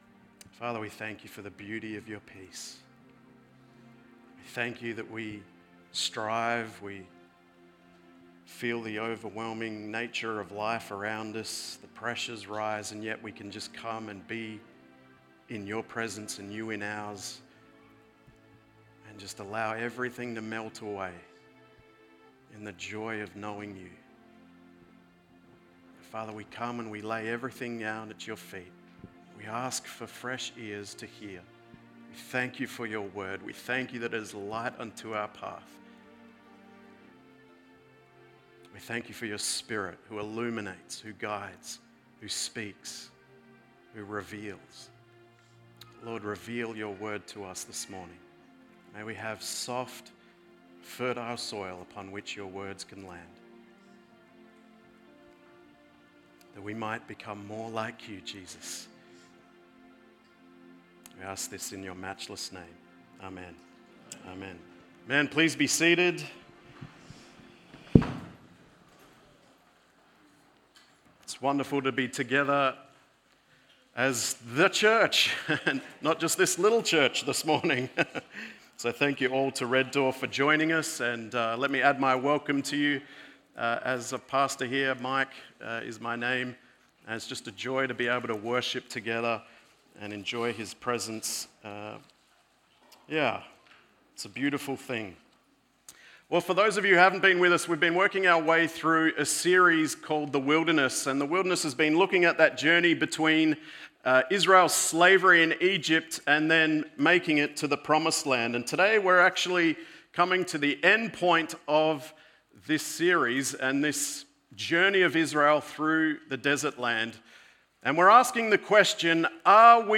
Sermons | Coolbellup Community Church